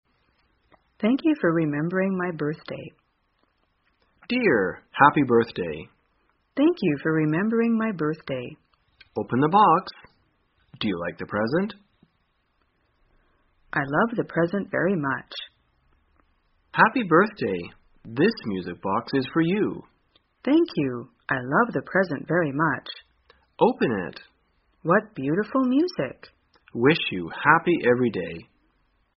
在线英语听力室生活口语天天说 第277期:怎样祝贺生日的听力文件下载,《生活口语天天说》栏目将日常生活中最常用到的口语句型进行收集和重点讲解。真人发音配字幕帮助英语爱好者们练习听力并进行口语跟读。